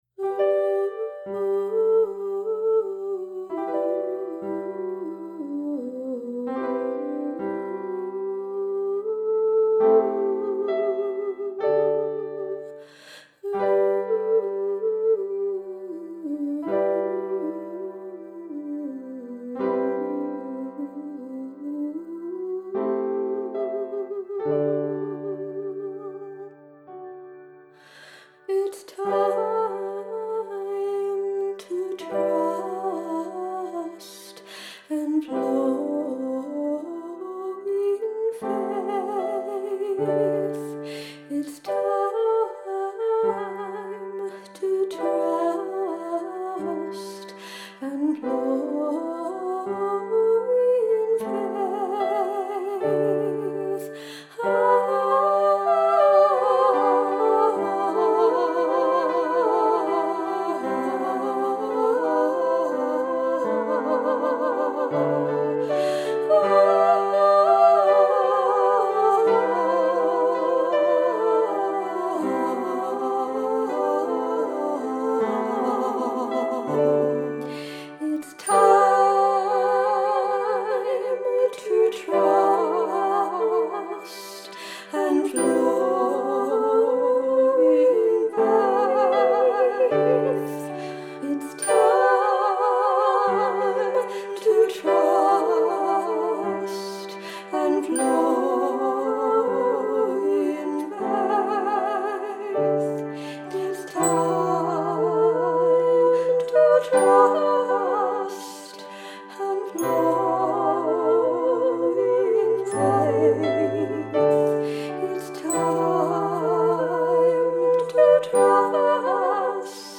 Piano and Soundscape